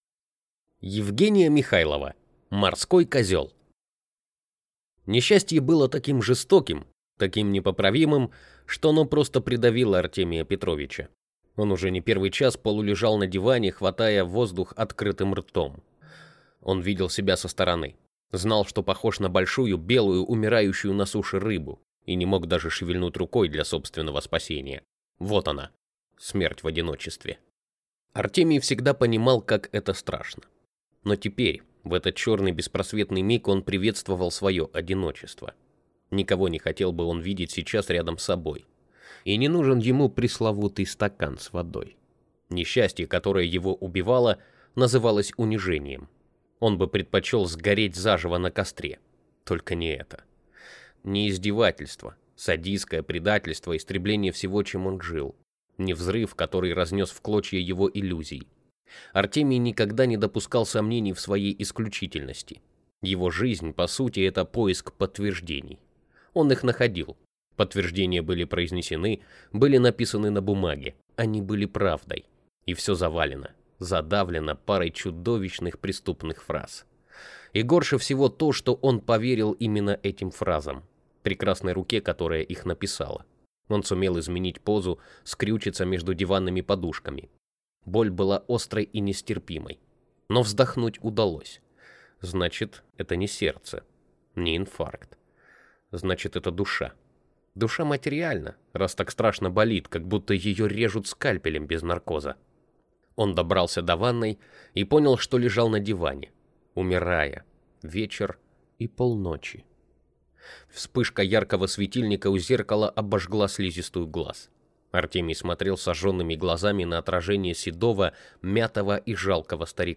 Аудиокнига Морской козел (рассказ) | Библиотека аудиокниг